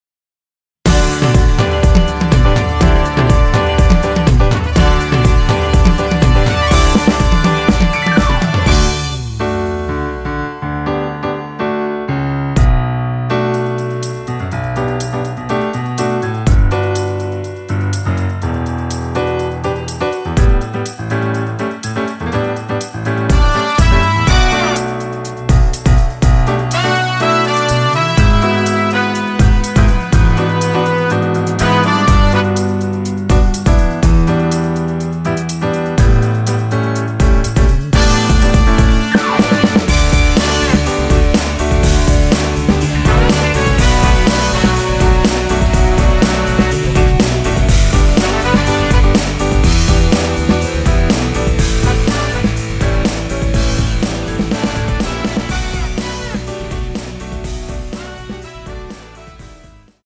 원키 (1절+후렴)으로 진행되는 멜로디 포함된 MR입니다.(미리듣기 확인)
노래방에서 노래를 부르실때 노래 부분에 가이드 멜로디가 따라 나와서
앞부분30초, 뒷부분30초씩 편집해서 올려 드리고 있습니다.
중간에 음이 끈어지고 다시 나오는 이유는